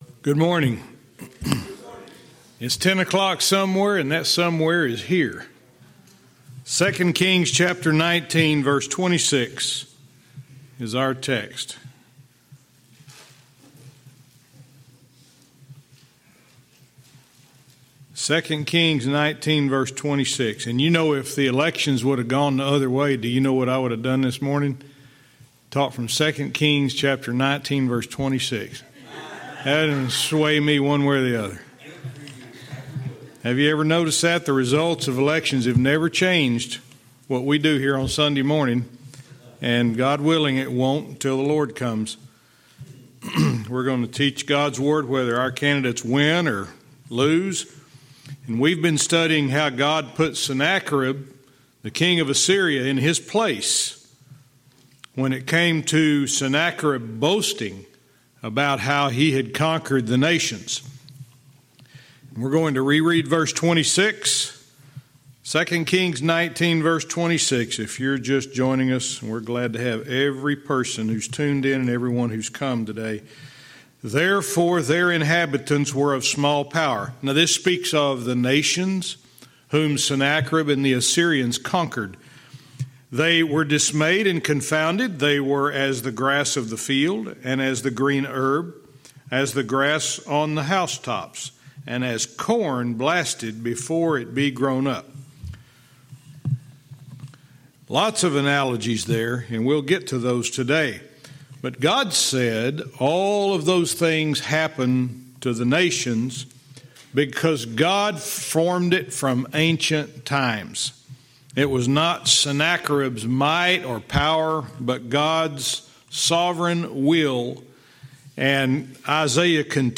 Verse by verse teaching - 2 Kings 19:26(cont)-27